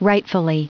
Prononciation audio / Fichier audio de RIGHTFULLY en anglais
Prononciation du mot : rightfully
rightfully.wav